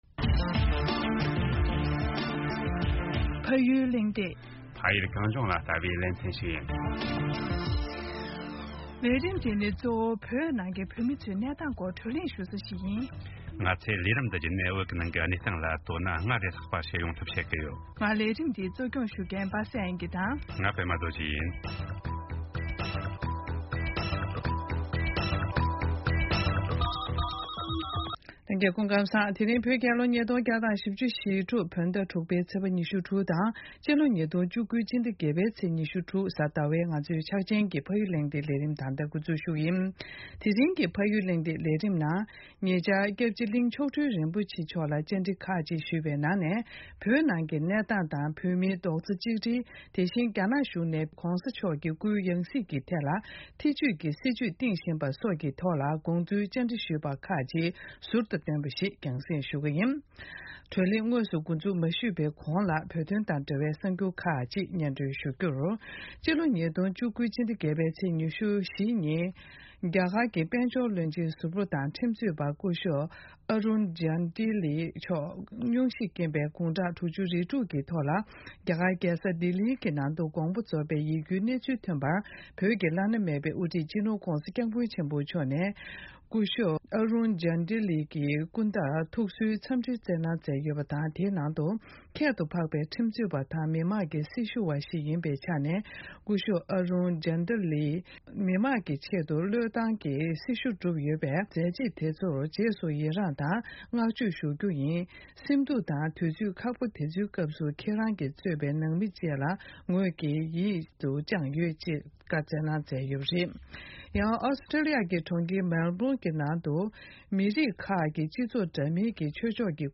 སྐྱབས་རྗེ་གླིང་མཆོག་སྤྲུལ་རིན་པོ་ཆེར་བཅར་འདྲི།